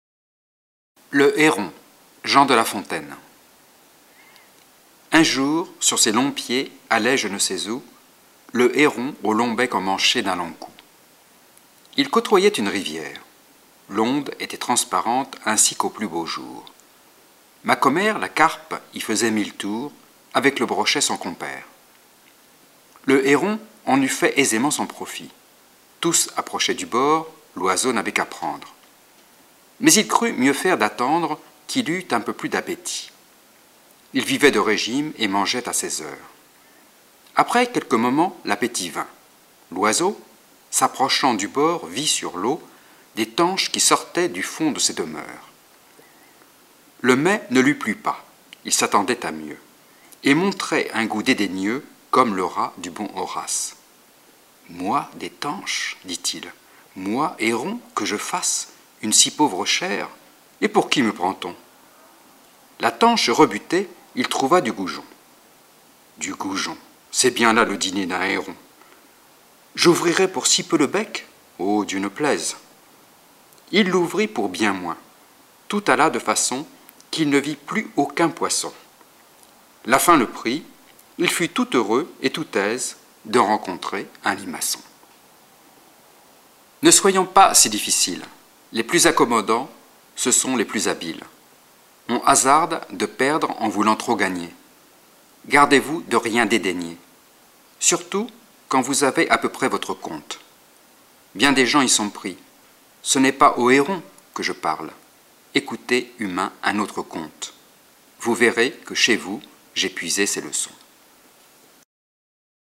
フランス人による朗読音声